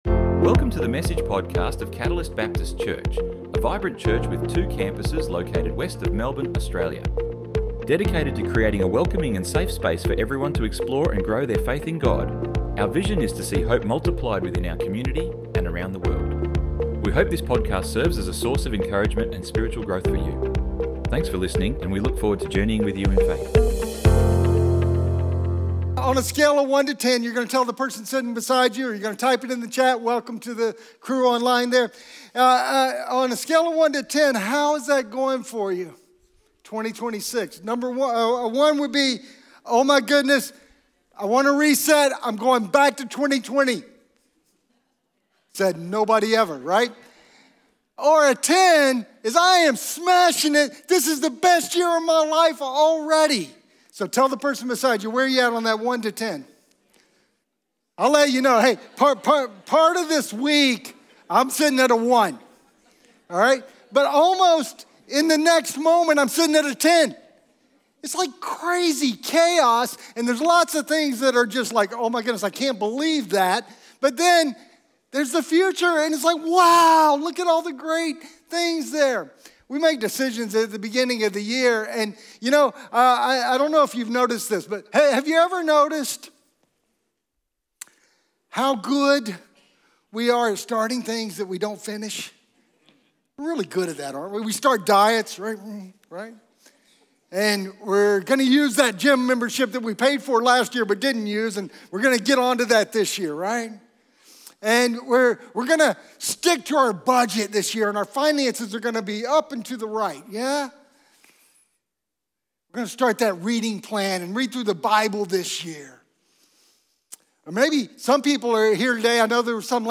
Download Download Sermon Notes 03 - online notes - Are you for Real - living by faith.docx We are really good at starting things and not finishing them!